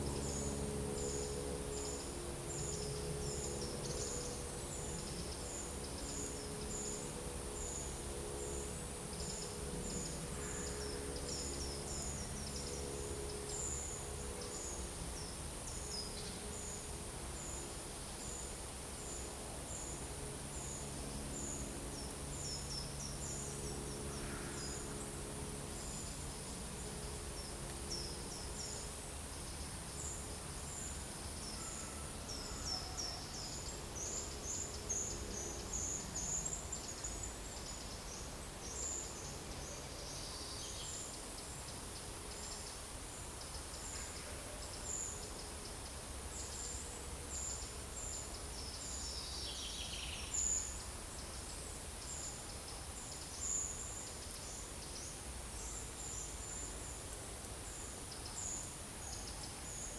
Monitor PAM
Certhia familiaris
Troglodytes troglodytes
Certhia brachydactyla
Regulus ignicapilla
Alcedo atthis